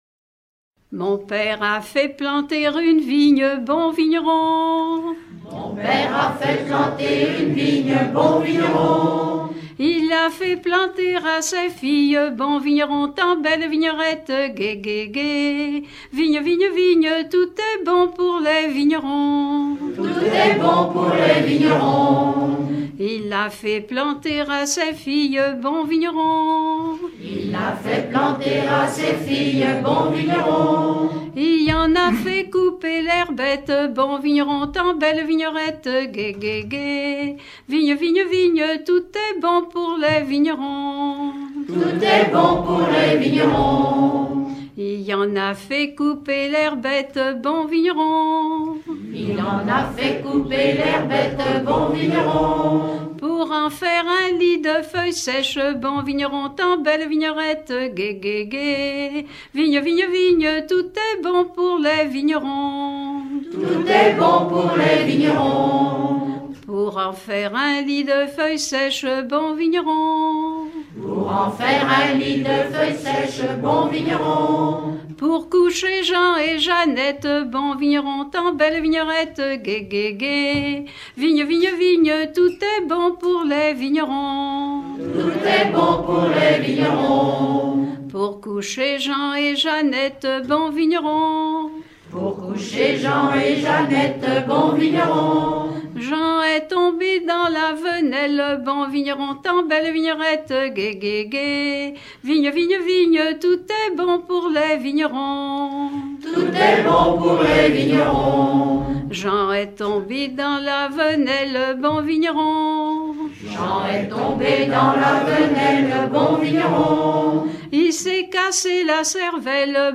Enumératives - Enumératives diverses
Genre laisse
Pièce musicale éditée